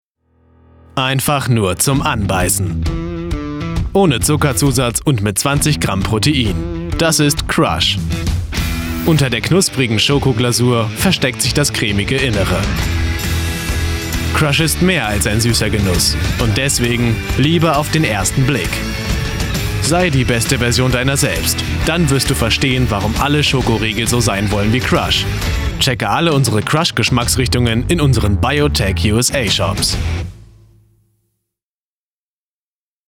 Kein Dialekt
Sprechprobe: Sonstiges (Muttersprache):